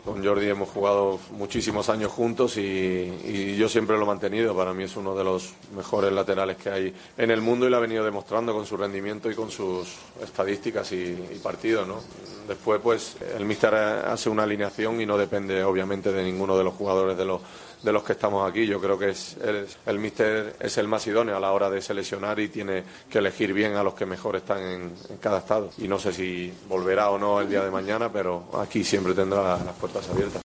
En la sala de prensa del Benito Villamarín, escenario del choque del lunes, el defensa madridista dijo este domingo que en este asunto la decisión sólo le compete al seleccionador y desconoce si Jordi Alba "volverá o no" al combinado español, "pero aquí siempre tendrá las puertas abiertas".